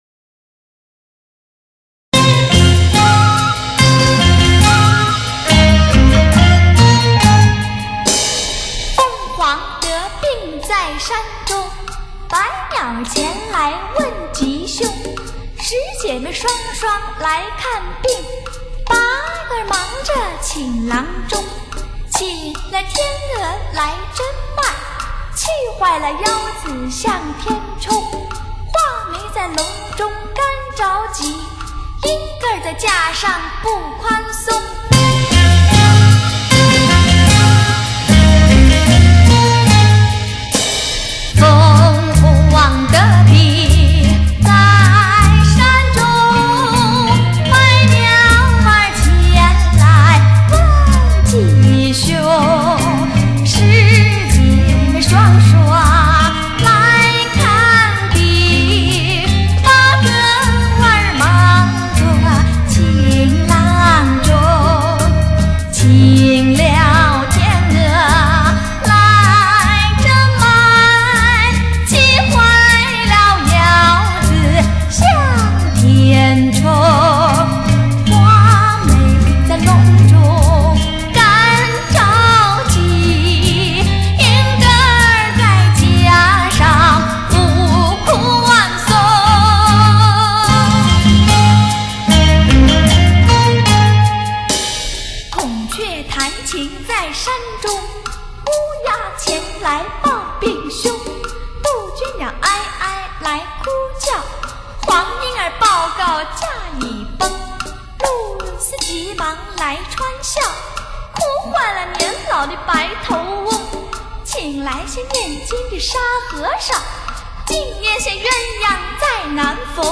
磁性迷人